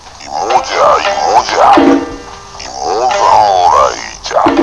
と次々に歴史上の人物達の語りかけです。